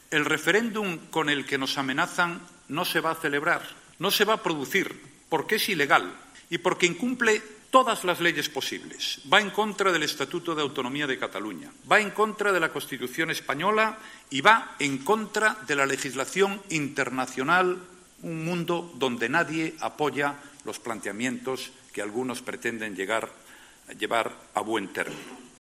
Rajoy ha aludido a la situación en Cataluña en su intervención en la jornada inaugural de la Escuela Miguel Ángel Blanco convocada en Bilbao por Nuevas Generaciones, la organización juvenil del PP.